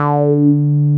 RUBBER D4 F.wav